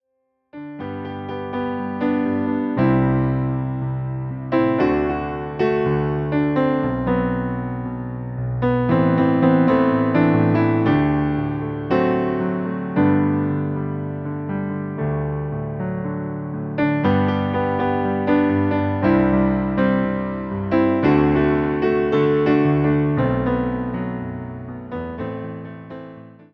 Wersja demonstracyjna:
59 BPM
G – dur